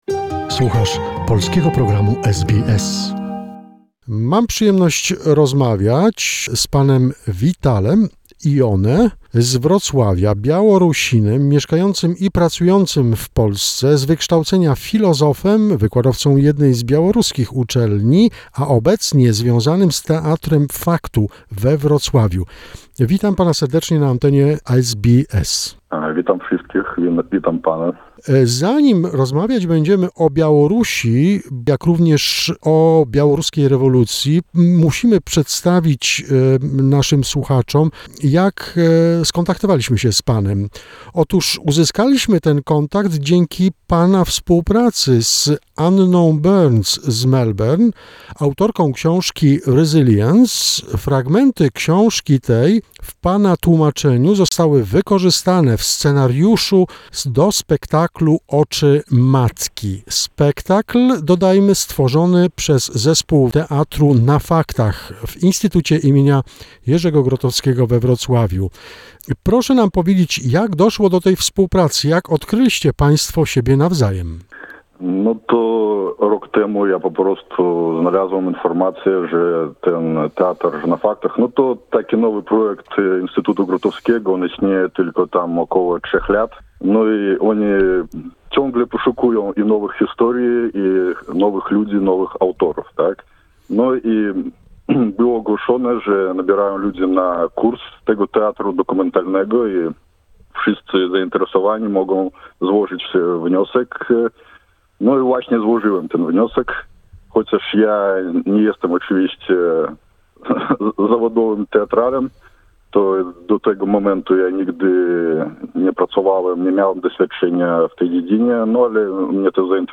This is the first part of the interview .... the next one is coming soon.